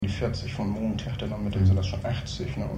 4.6 Unverständliches und schwer Verständliches